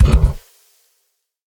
Minecraft Version Minecraft Version snapshot Latest Release | Latest Snapshot snapshot / assets / minecraft / sounds / mob / camel / dash2.ogg Compare With Compare With Latest Release | Latest Snapshot